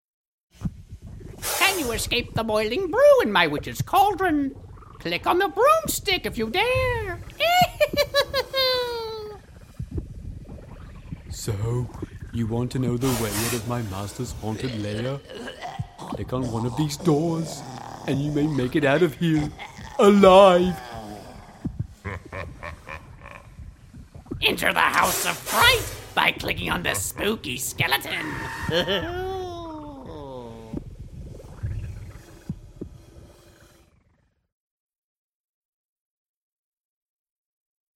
narration, amusing, funny
mid-atlantic
Sprechprobe: Sonstiges (Muttersprache):